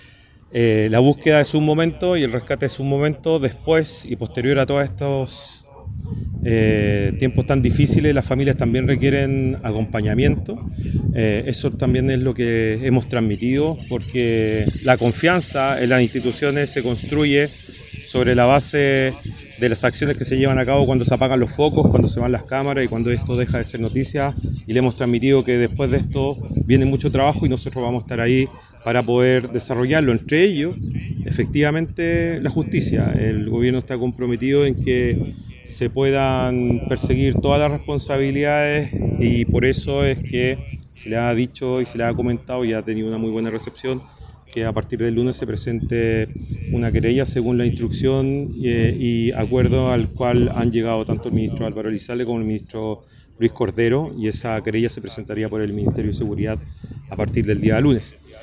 Respecto de la querella, el subsecretario del Interior, Víctor Ramos, señaló que “el Gobierno está comprometido en que se puedan perseguir todas las responsabilidades” y anunció que la acción judicial será presentada el lunes por el Ministerio de Seguridad Pública.